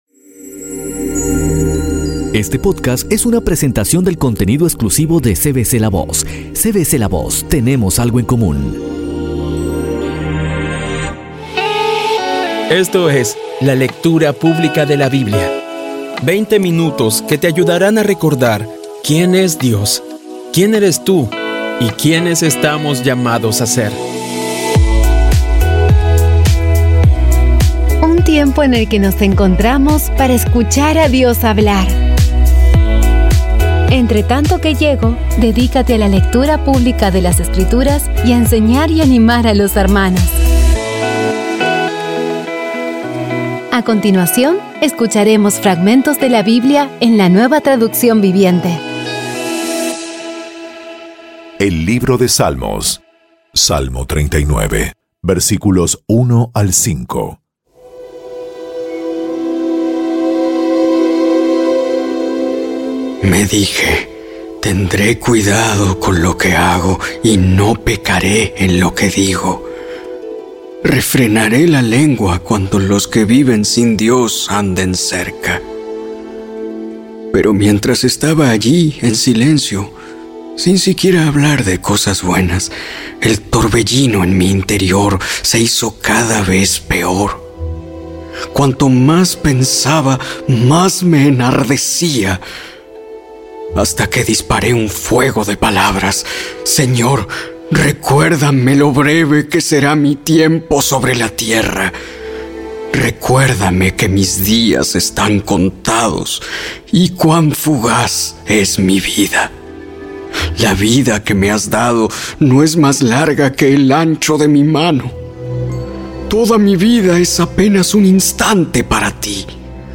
Audio Biblia Dramatizada Episodio 85
Poco a poco y con las maravillosas voces actuadas de los protagonistas vas degustando las palabras de esa guía que Dios nos dio.